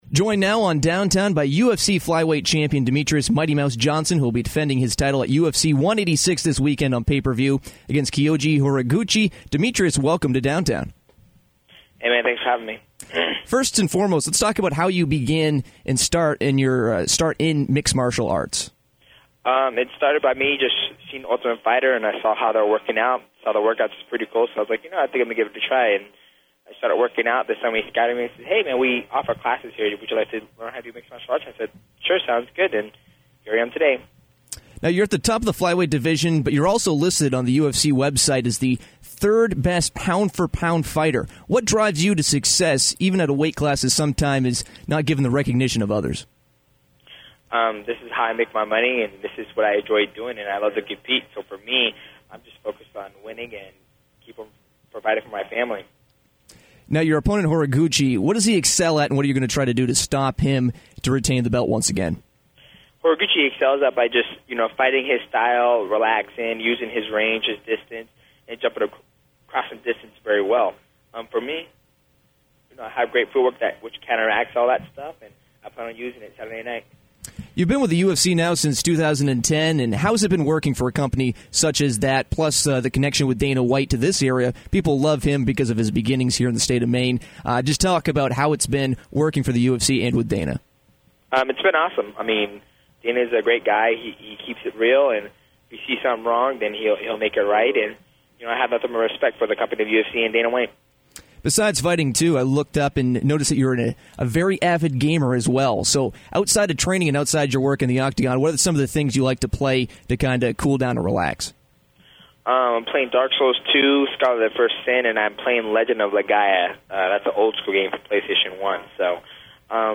UFC flyweight champion Demetrious Johnson joined Downtown on Thursday afternoon to talk about his upcoming fight with Kyoji Horiguchi at UFC 186. He talked about how he got into mixed martial arts, as well as how he plans to limit Horiguchi’s attacks this weekend. Johnson also talked about his favorite video games to play in his downtime and what it was like playing as himself in the EA Sports UFC video game.